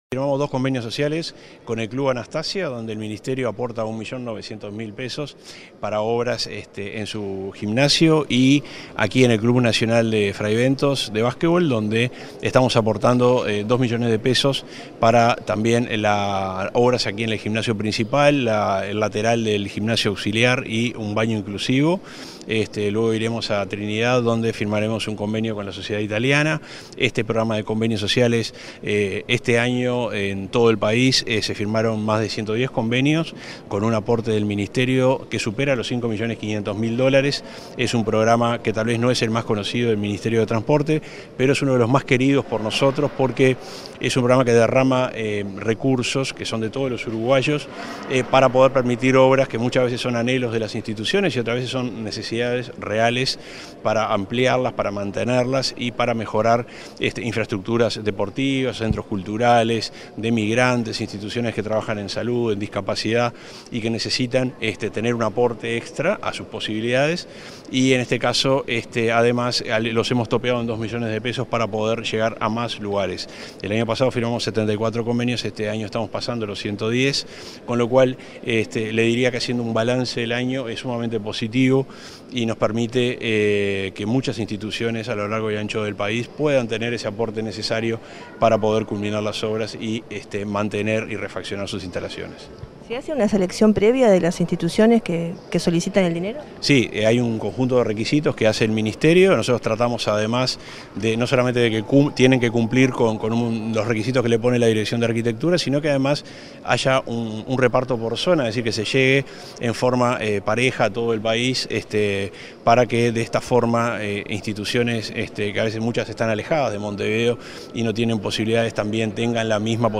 Entrevista al subsecretario de Transporte, Juan José Olaizola